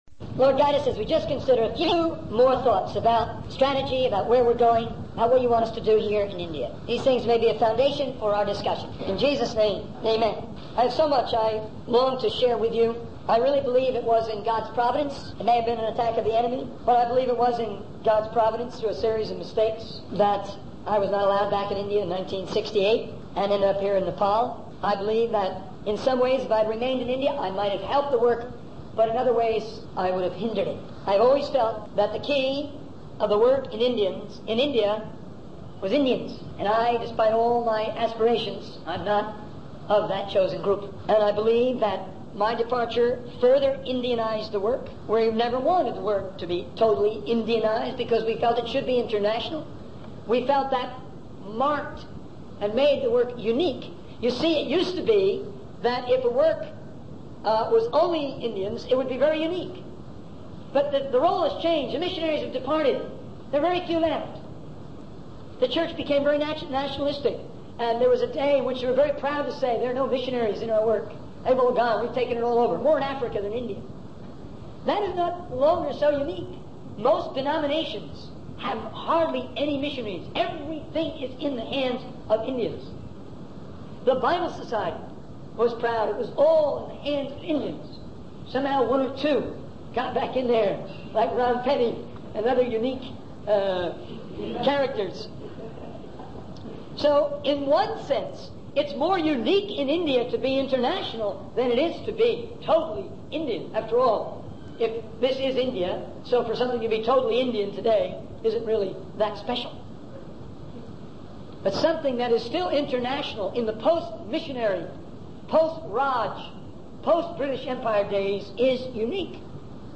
In this sermon, the speaker emphasizes the importance of unity and like-mindedness among believers. He warns that without agreement and a common strategy, there will be divisions and contentions within the church. The speaker also highlights the need for a plan of action and policy to carry out the work of world evangelism, even if there are disagreements.